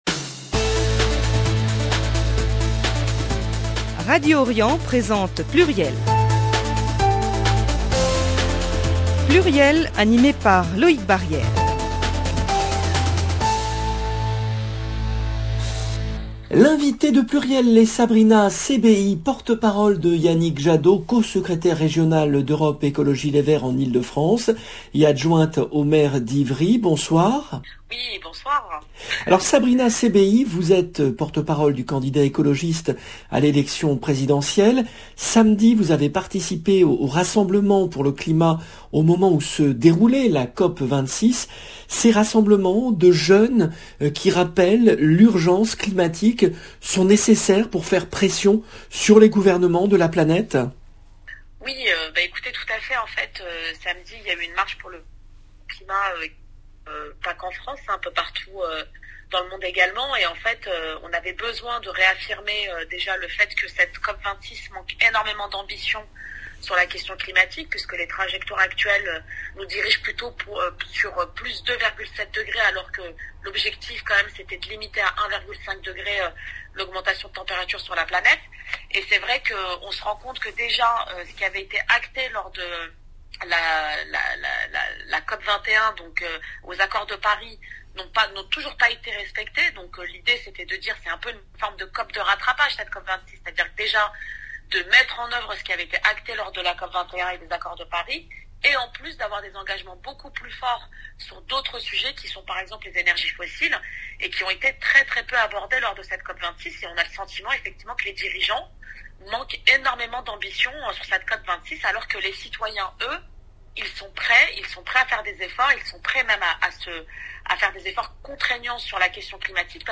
le rendez-vous politique du lundi 8 novembre 2021 L’invitée de PLURIEL est Sabrina Sebaihi, porte-parole de Yannick Jadot, co-secrétaire régionale d’EELV en Ile-de-France et adjointe au maire d’Ivry.